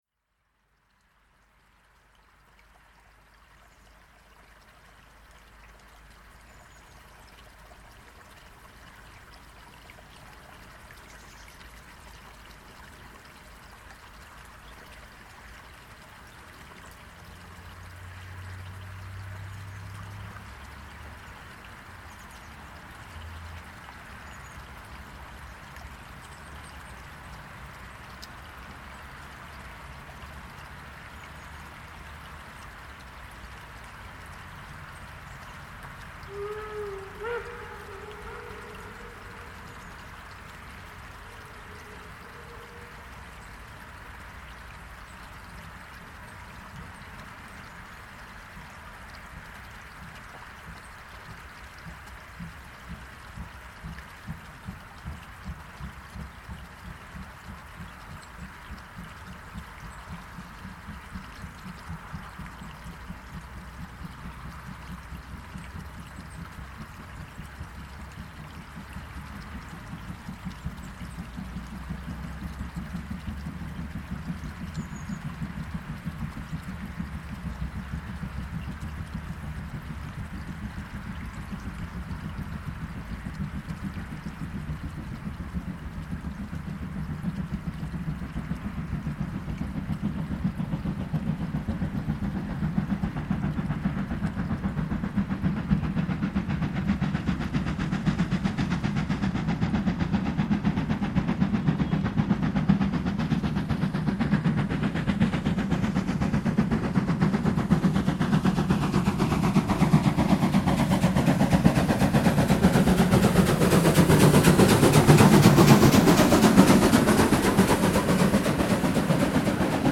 Der Zug war nun etwas länger(?) und schwerer als im Jahr zuvor und die 50er machte richtig guten Einheitslok-Sound, zu meinem Erstaunen:
50 2273 (ex Lok 50 3539) mit 1. Nikolauszug nach Neuffen, hinter Linsenhofen an einem kleinen Nebenbach der Steinach und nah am bahnparallelen Feldweg, um 11:27h am 14.12.2025.   Hier anhören: